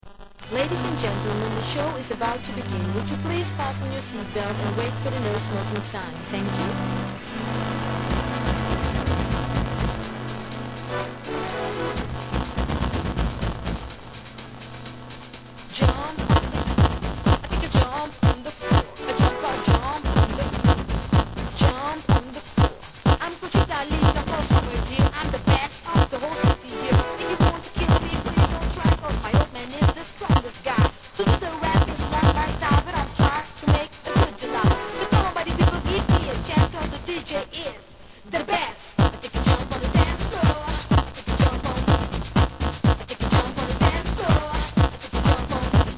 HIP HOP/R&B
ペナペナシンセのHIP HOUSE!!